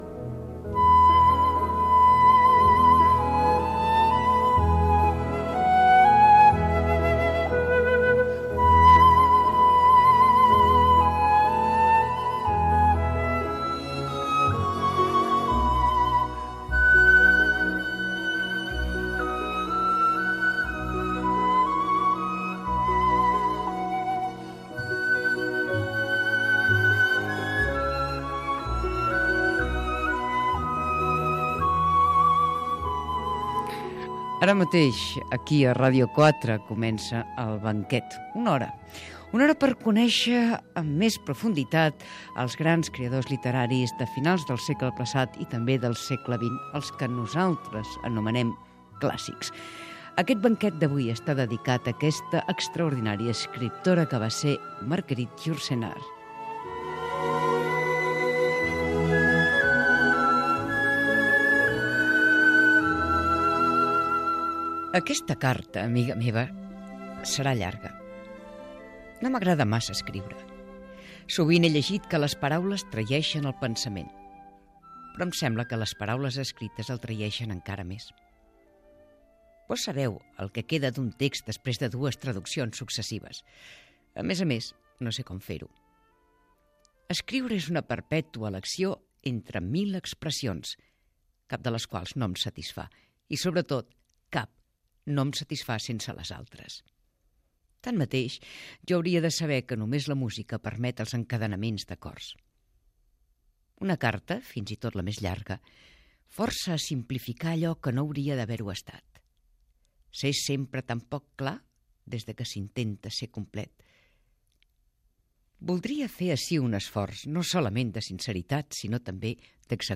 Presentació, lectura d'un fragment literari de l'escriptora Marguerite Yourcenar i entrevista
Gènere radiofònic Cultura